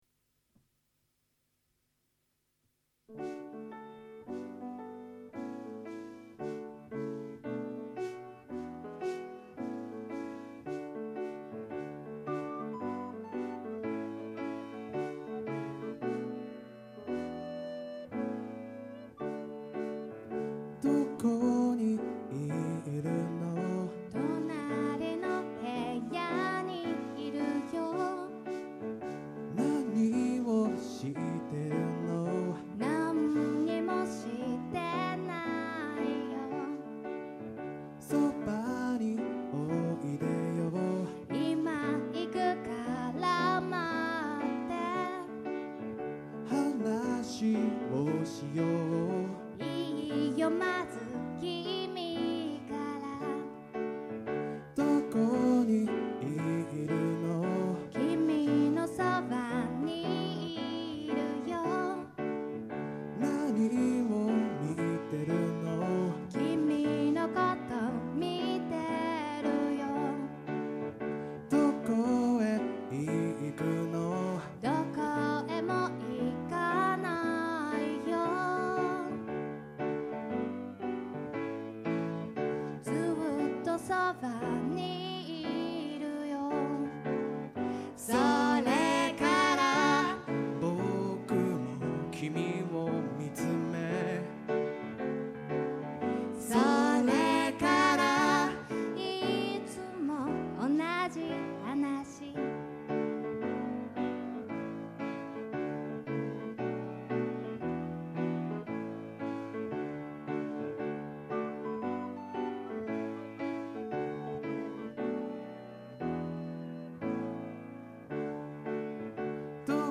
4/11ワンマンでもっとも反響があったこの曲★
私のリコーダーも(笑)